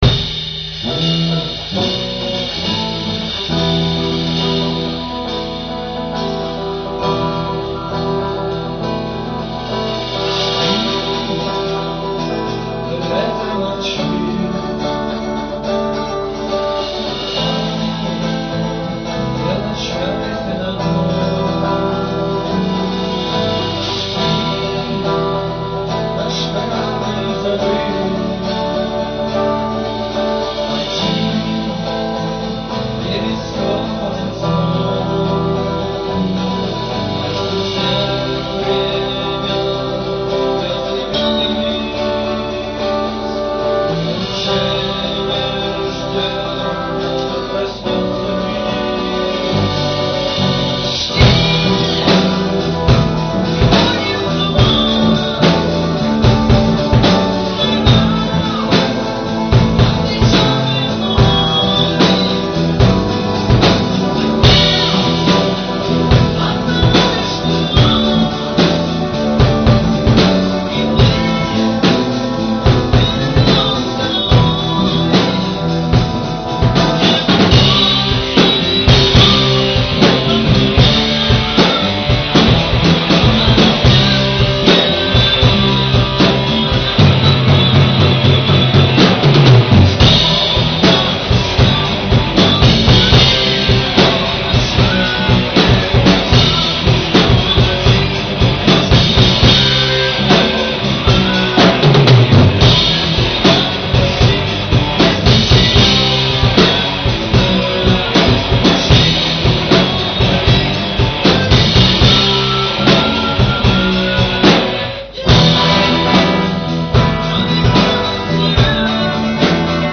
Запись с репетиций